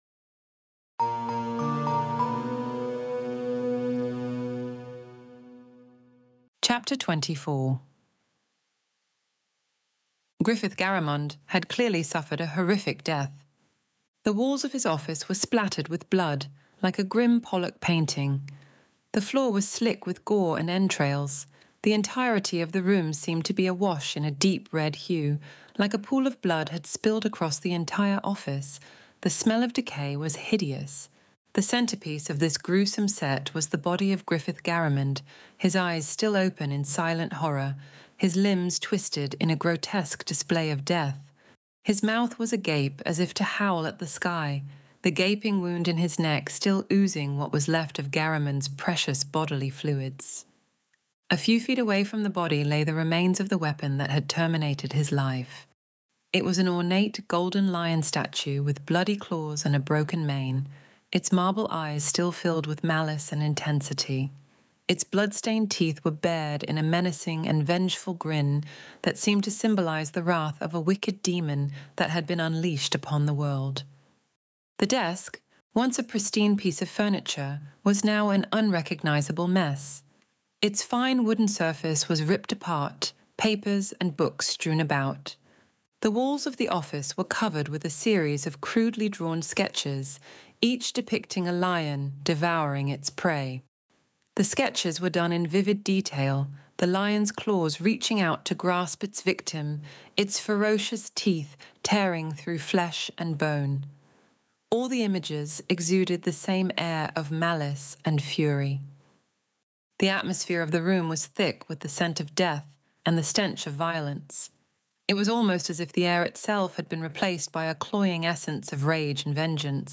Extinction Event Audiobook Chapter 24